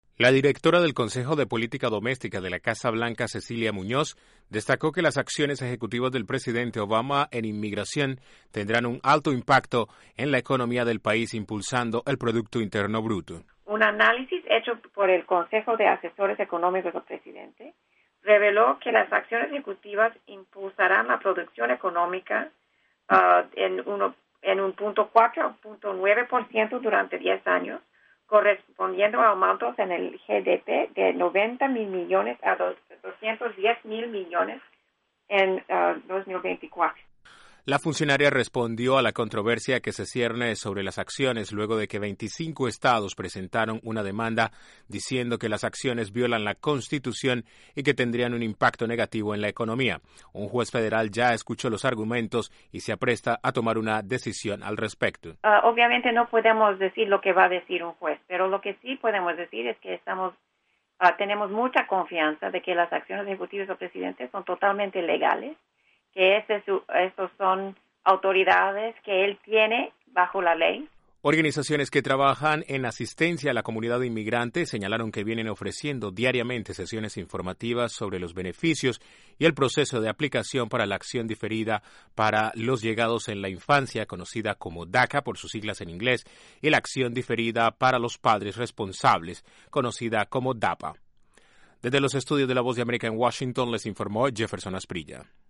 La Casa Blanca subrayó los beneficios económicos de las medidas ejecutivas en inmigración y respondió a la controversia por la demanda de 25 estados contra las medidas. Desde la Voz de América en Washington informa